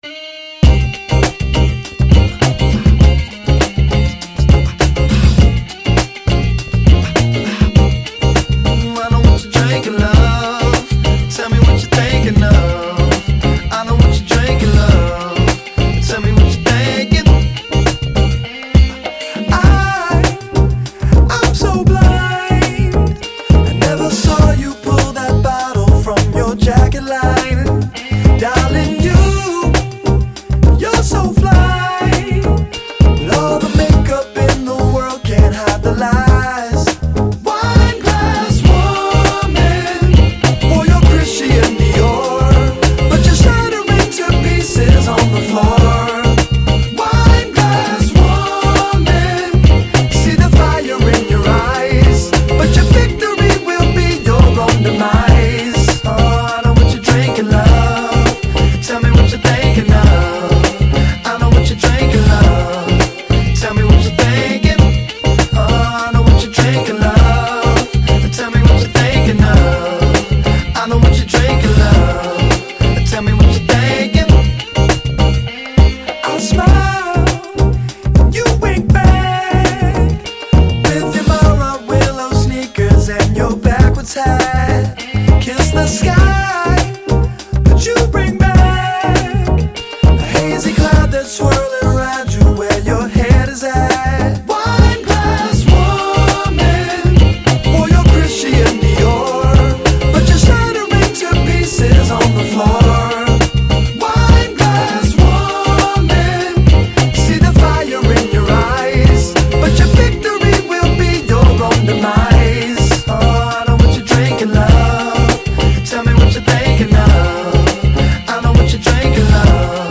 funk-soul
sprinkles more 70’s sounds into the stew of retro-soul